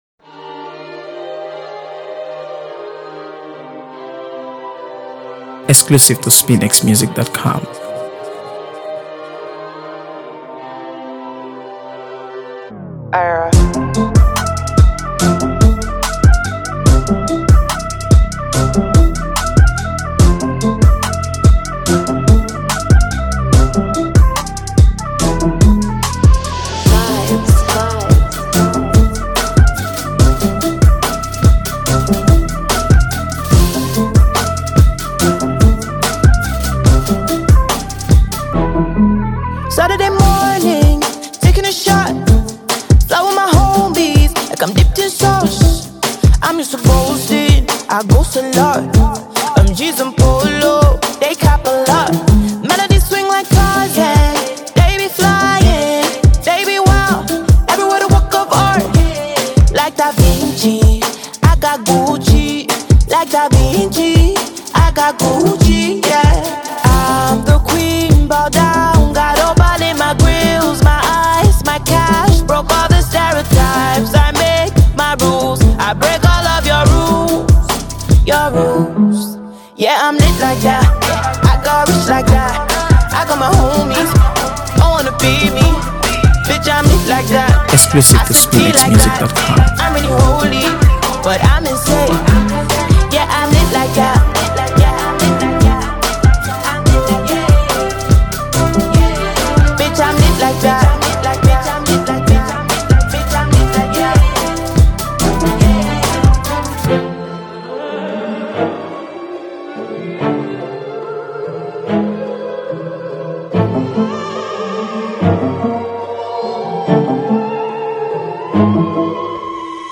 AfroBeats | AfroBeats songs
Nigerian female artist